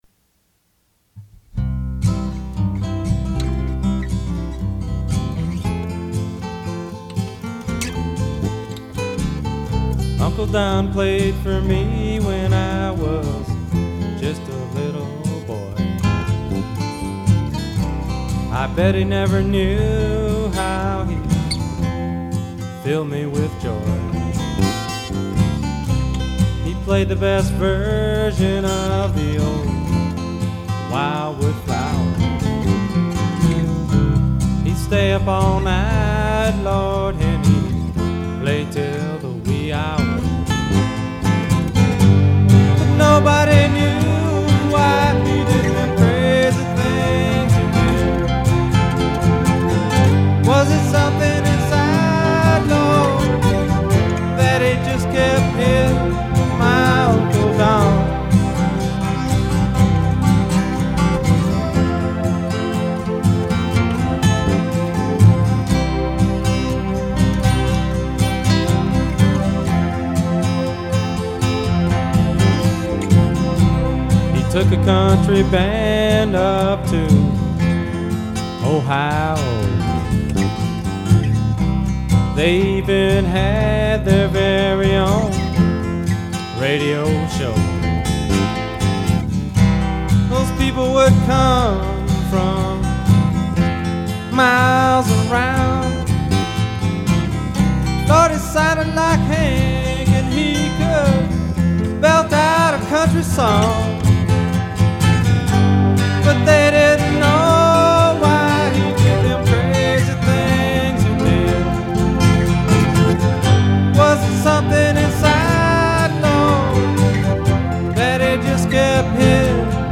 He wrote the songs and was the band’s lead singer and guitar player.